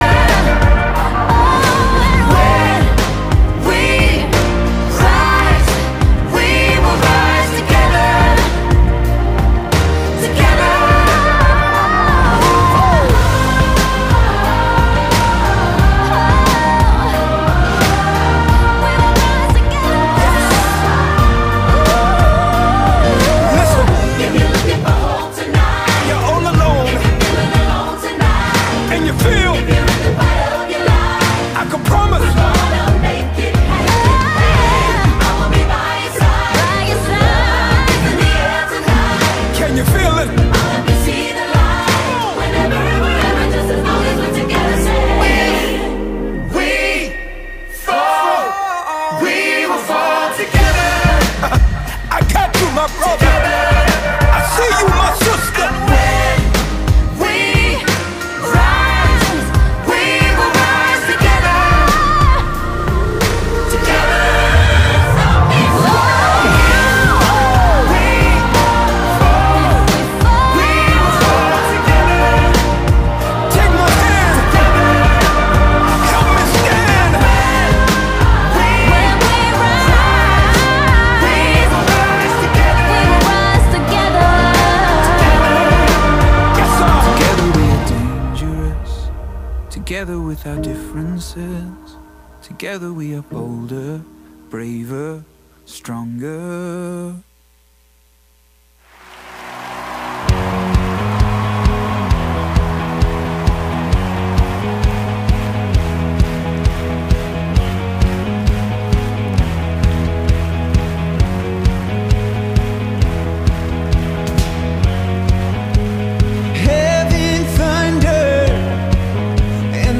Sermons
Weekly sermons, Trinity Bible Church, Bible teaching, hope!